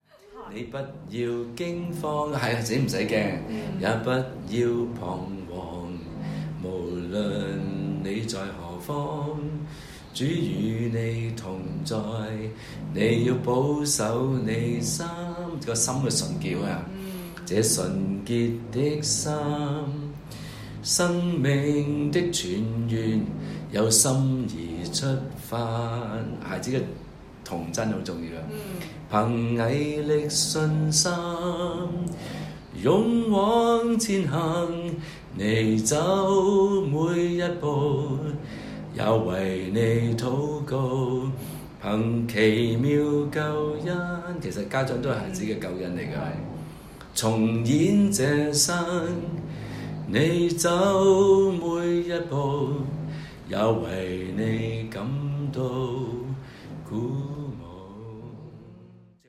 校長演唱版本 )：